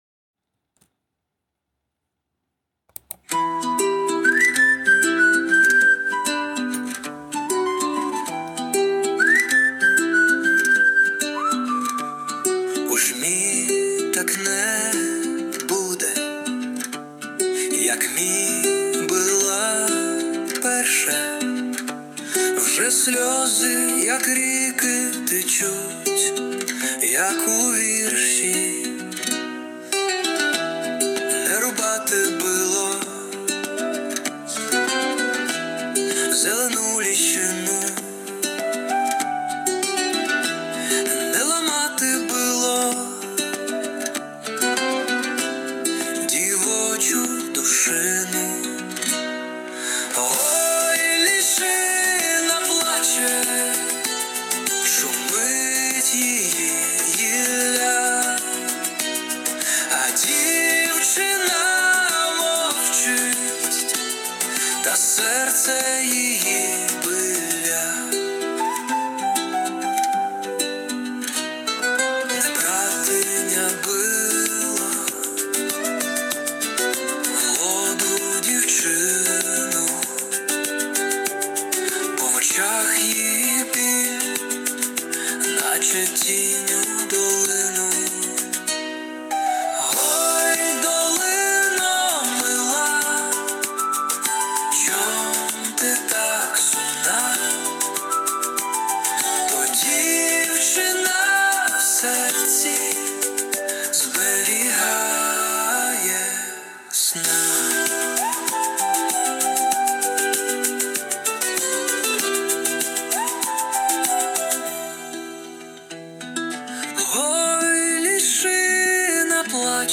СТИЛЬОВІ ЖАНРИ: Ліричний
12 Гарна лірична пісня!